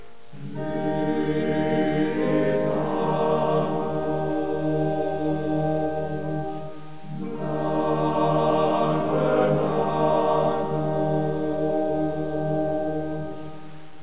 The songs were sung by different choirs as: Ljubljanski oktet, Slovenski komorni zbor, Deseti brat oktet,